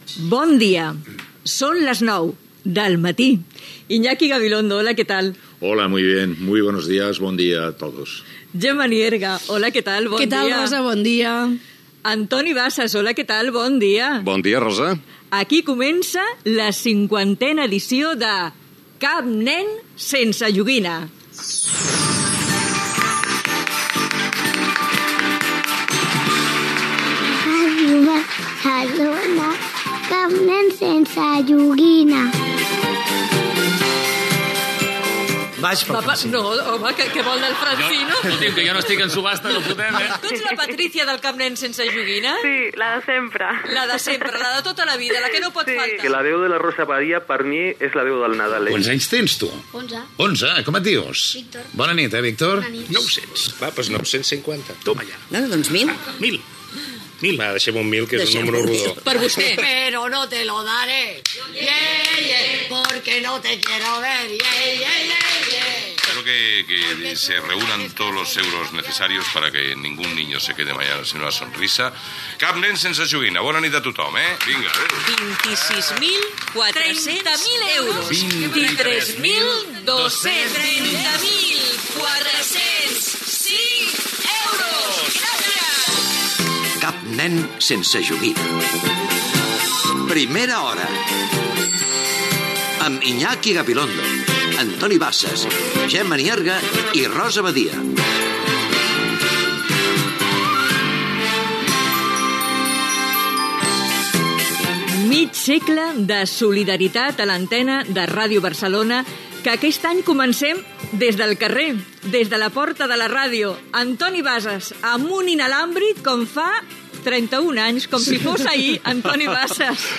31859fd3bf6338fc89107e0f068dc93843c841ea.mp3 Títol Ràdio Barcelona FM Emissora Ràdio Barcelona FM Cadena SER Titularitat Privada estatal Nom programa Cap nen sense joguina Descripció Hora, inici de la 50ena edició del programa: resum sonor d'anys passats, indicatiu del programa, connexió al carrer, entrevista al president de la Generalitat Carles Puigdemont i l'alcaldessa de Barcelona, Ada Colau, que aporten objectes a la subhasta, resum de veus, comentaris sobre el programa des de l'estudi Toreski i participació del música Manu Guix que interpreta un tema original per al programa.